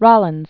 (rŏlĭnz), Theodore Walter Known as "Sonny."